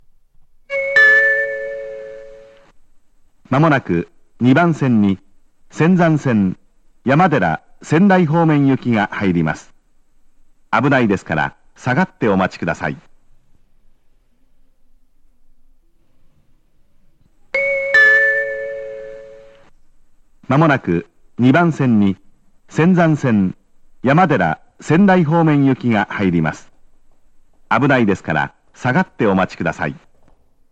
山寺・仙台方面接近放送
●上りが男声、下りが女声の、路線名まで言ってくれる細かいアナウンスです。
●接近チャイムは仙台駅仙石線と同じタイプ。
●スピーカー：ユニペックス小型